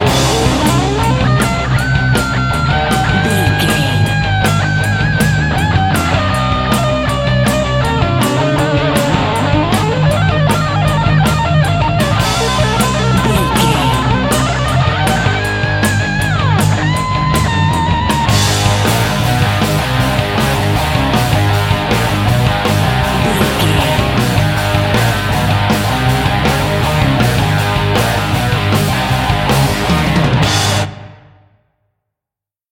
Aeolian/Minor
A♭
Fast
electric guitar
hard rock
lead guitar
bass
drums
aggressive
energetic
intense
nu metal
alternative metal